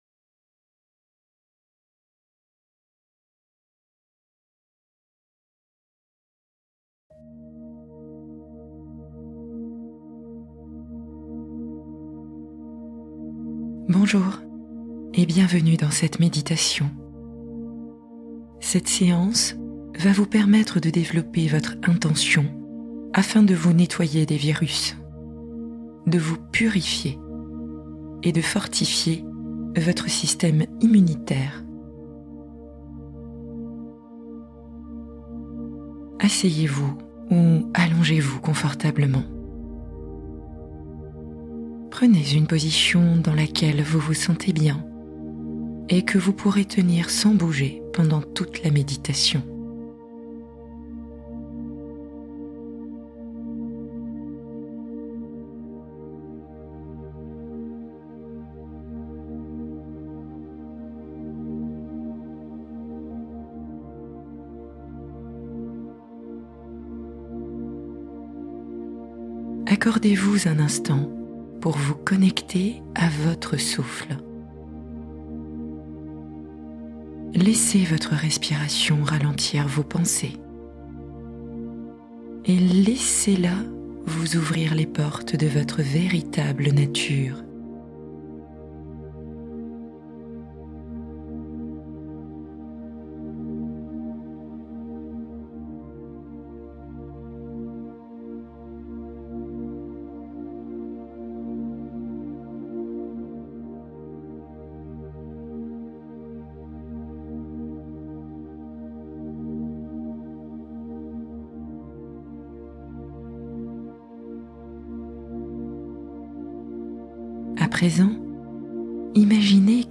L’amour bienveillant : méditation guidée pour ouvrir le cœur en douceur